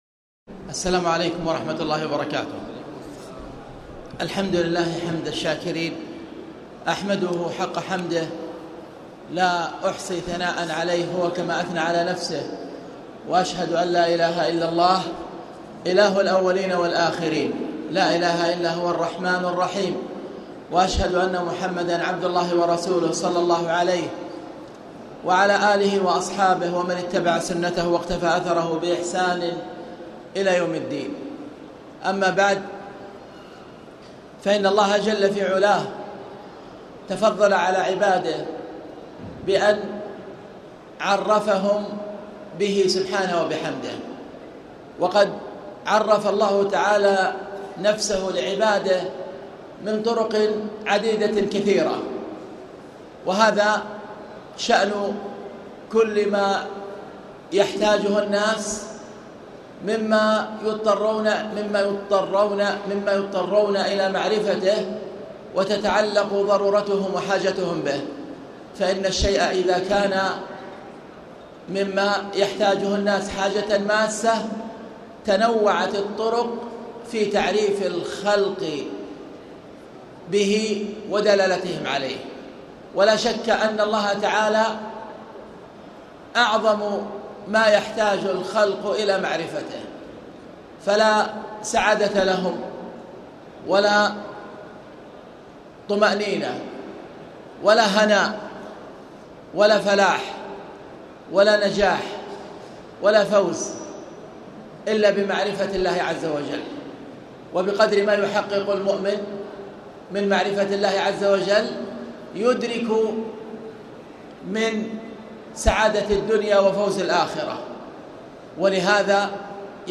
تاريخ النشر ١٩ رمضان ١٤٣٨ هـ المكان: المسجد الحرام الشيخ